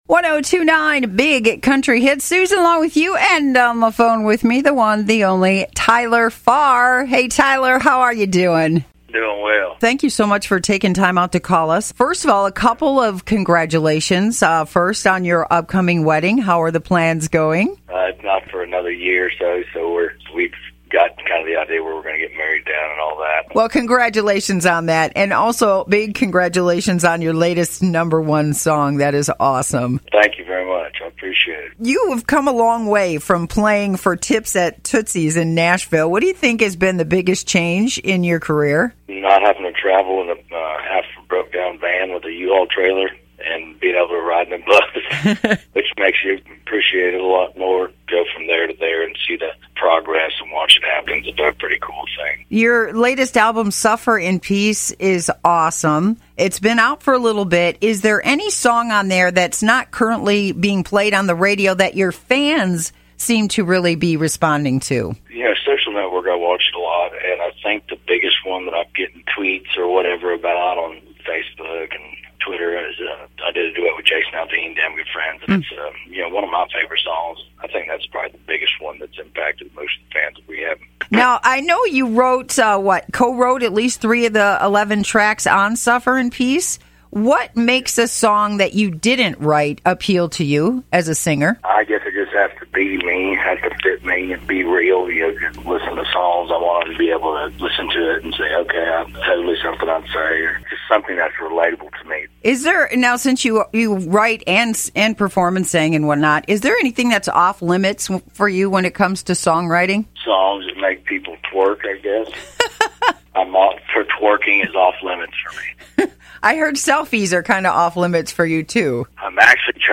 Interviews on Big Country 102.9